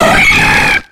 Cri de Scarhino dans Pokémon X et Y.